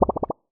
bubble_effect_03.wav